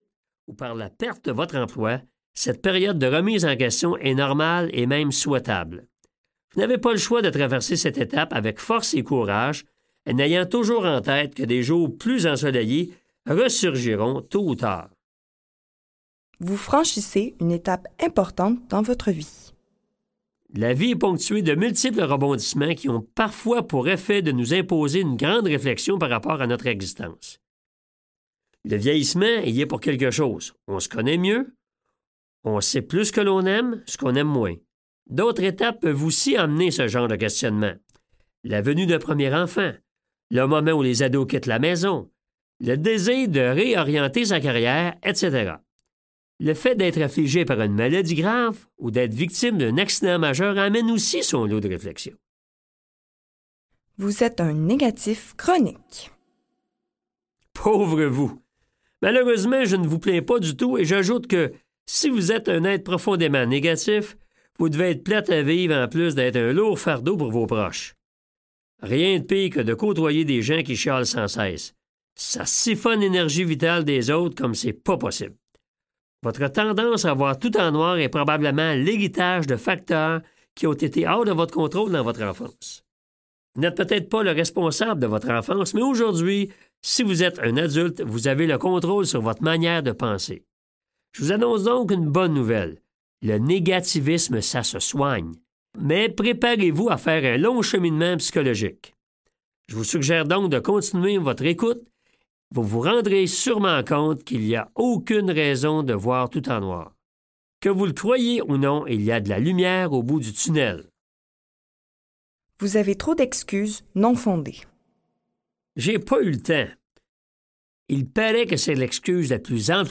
Le livre audio&#160